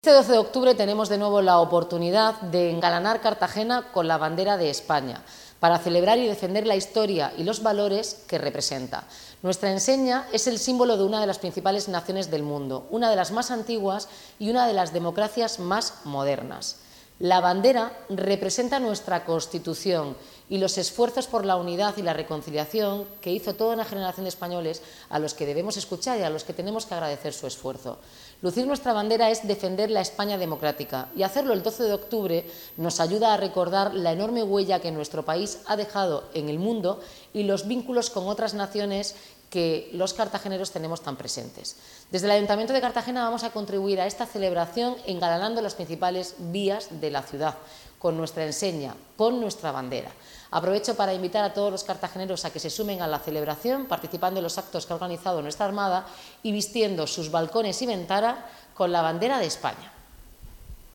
Enlace a Declaraciones de la alcaldesa sobre el bando de celebración de la Fiesta Nacional